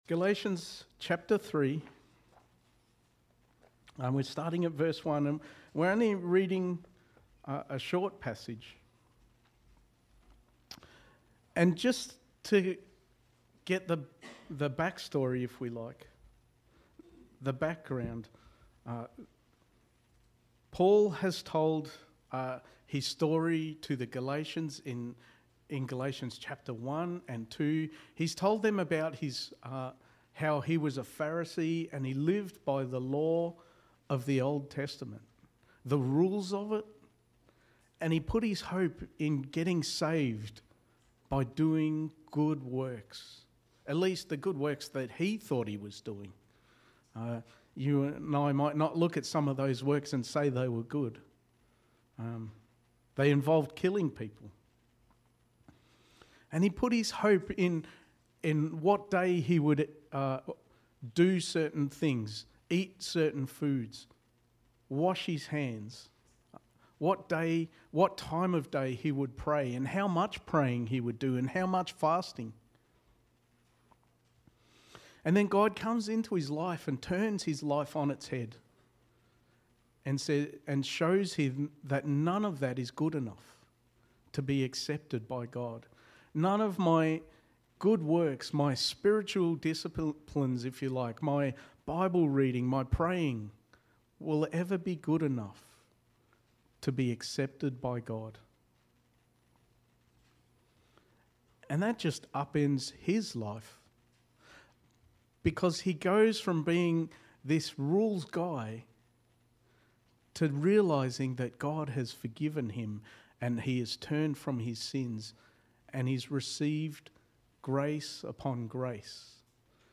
Sermon
Galatians 3:1-7 Service Type: Sunday Morning Sermon 6 « Christmas Stand-Alone Series 1